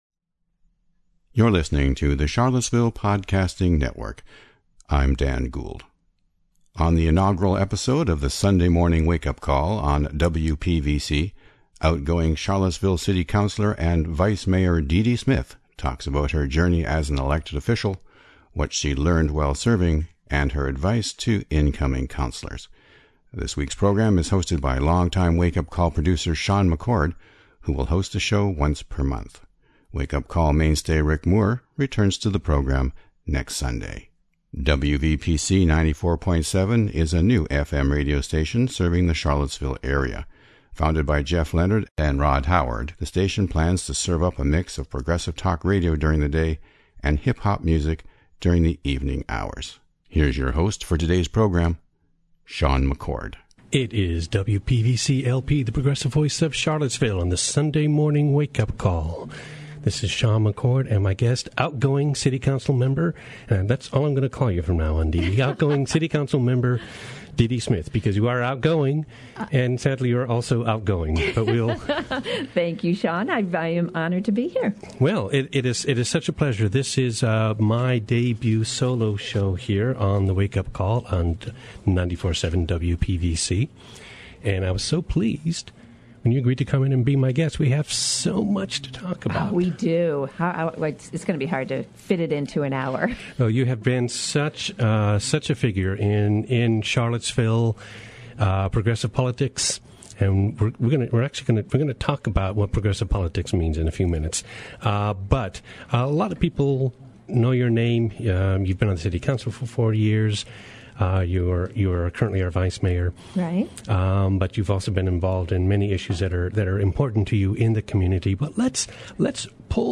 On the inaugural episode of the Sunday Morning Wake-Up Call on WPVC, outgoing Charlottesville City Councilor and Vice-Mayor Dede Smith talks about her journey as an elected official, what she learned while serving, and her advice to incoming councilors.
WPVC 94.7 is a new FM radio station serving the Charlottesville area.